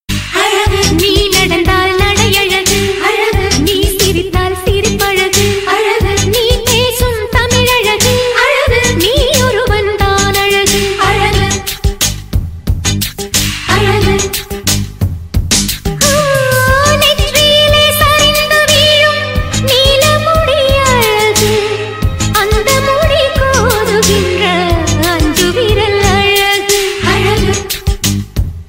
best flute ringtone download
romantic ringtone download